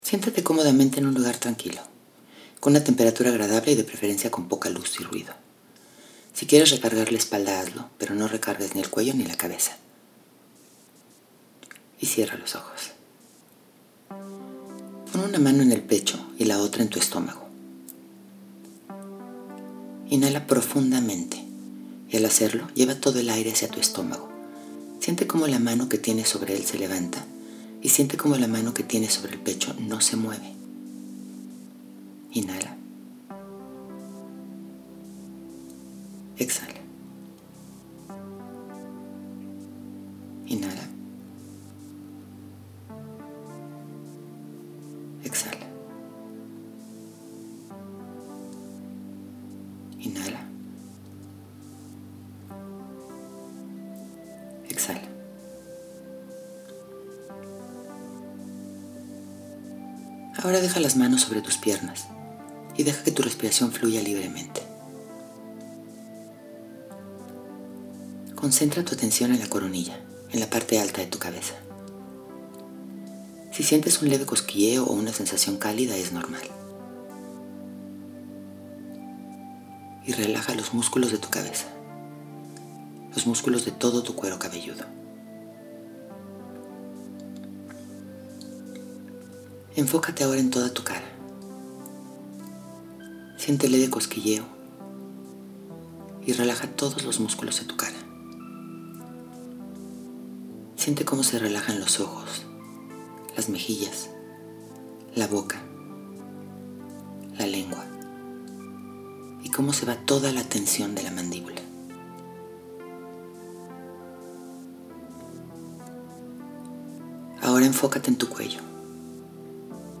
Somos cientos de personas las hemos logrado cambios importantes con esta meditación y lo mejor es que los hemos logrado sin sufrir en el proceso.